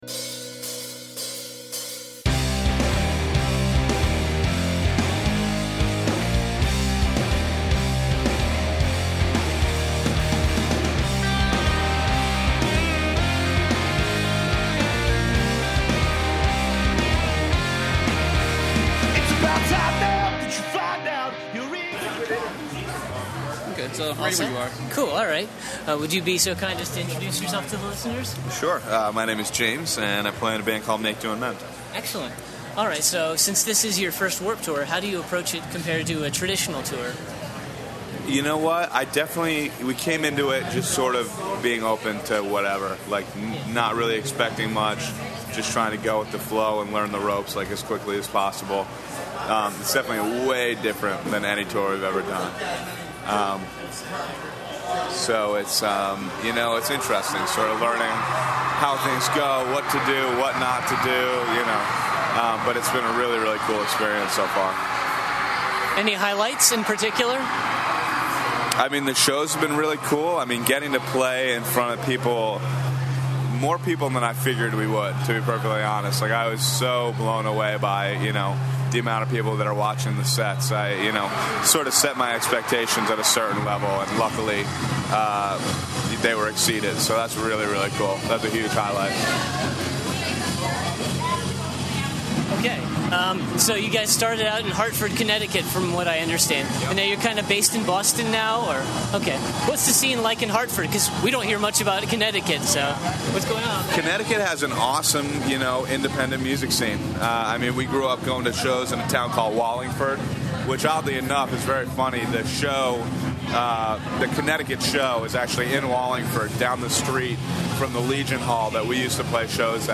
Exclusive: Make Do and Mend Interview
20-interview-make-do-and-mend.mp3